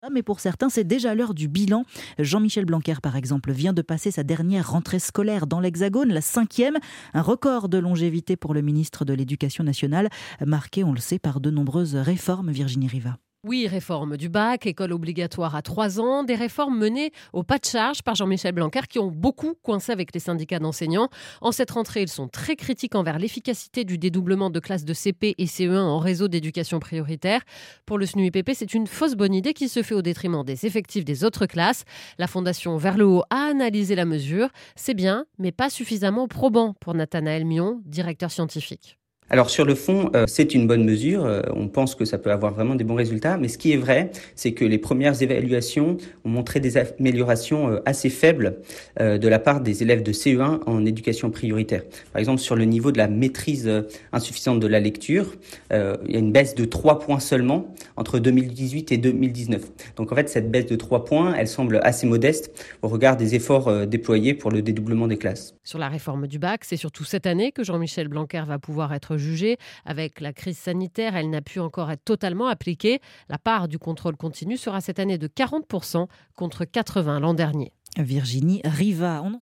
était dans la Matinale d’Europe 1 le 8 septembre. Il intervenait sur la question du dédoublement des classes de CP et CE1 en Réseau d’Éducation Prioritaire (REP) :